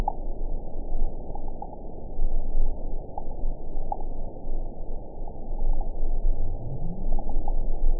event 913793 date 04/20/22 time 15:56:31 GMT (3 years, 1 month ago) score 5.44 location TSS-AB03 detected by nrw target species NRW annotations +NRW Spectrogram: Frequency (kHz) vs. Time (s) audio not available .wav